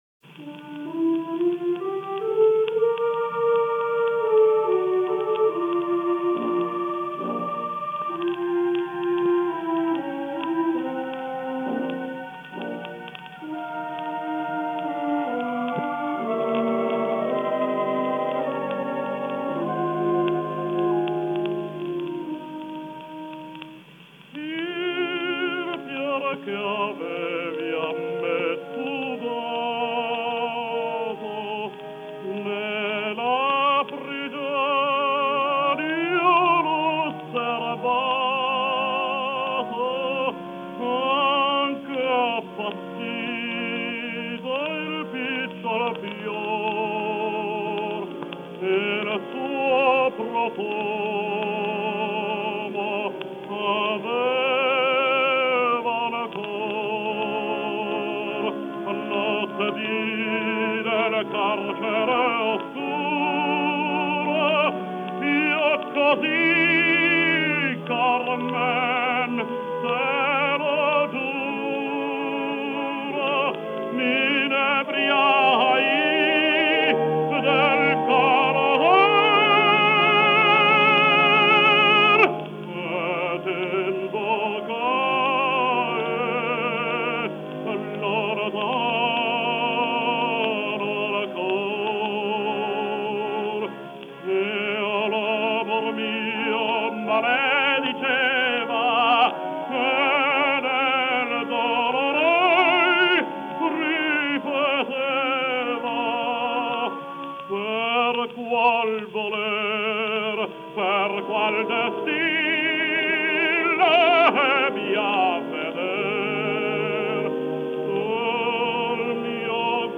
Жанр: Vocal